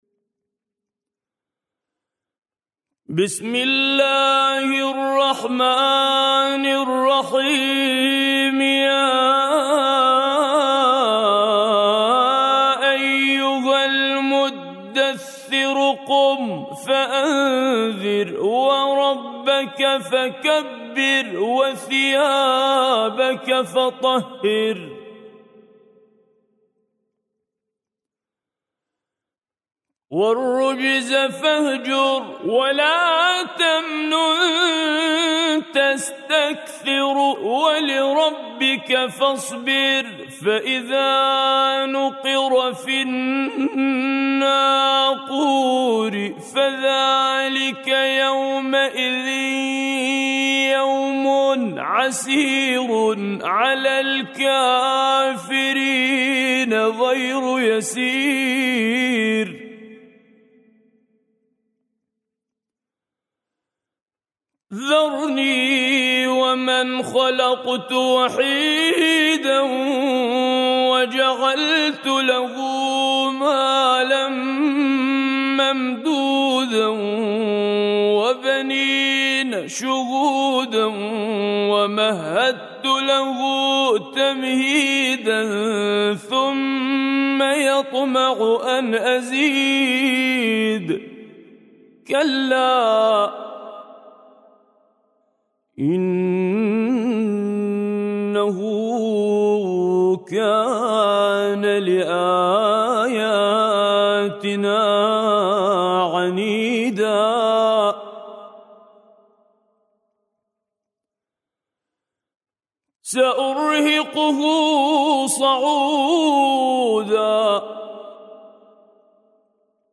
سورة المدثر- الطور العراقي - لحفظ الملف في مجلد خاص اضغط بالزر الأيمن هنا ثم اختر (حفظ الهدف باسم - Save Target As) واختر المكان المناسب